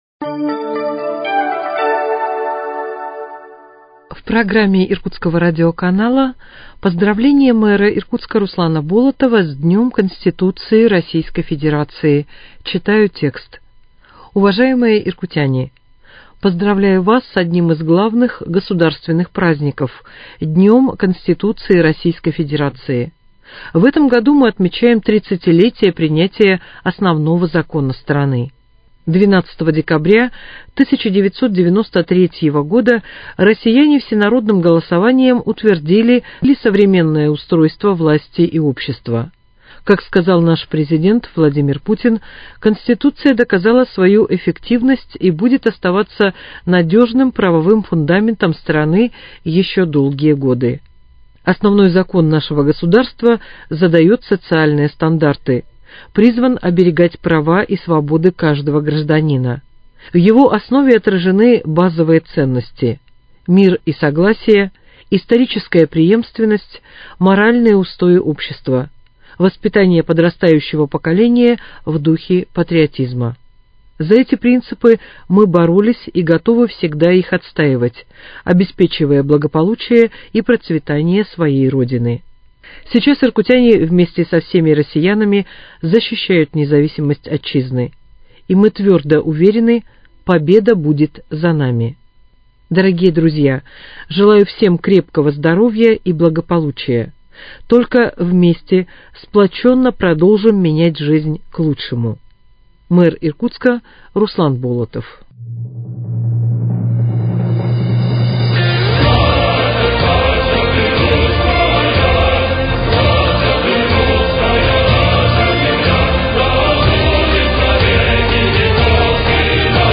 Поздравление мэра Иркутска Руслана Болотова с Днём Конституции Российской Федерации.